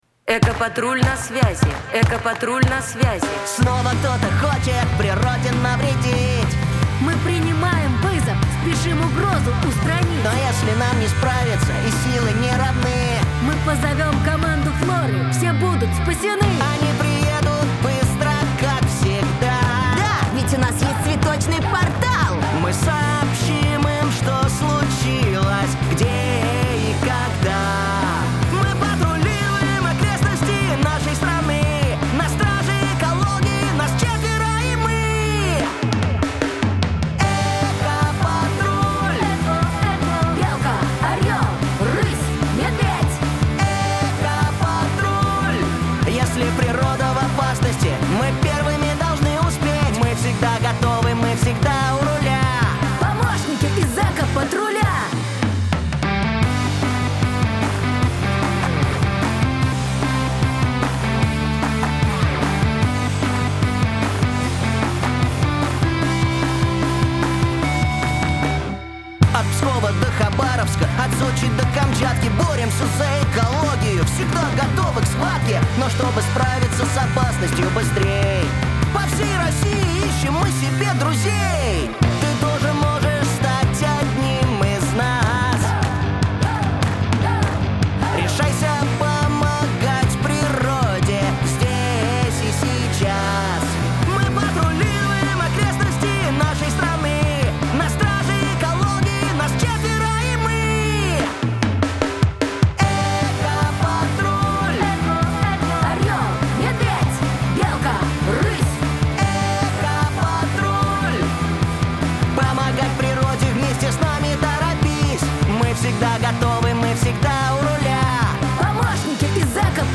ансамбль